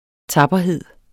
Udtale [ ˈtɑbʌˌheðˀ ]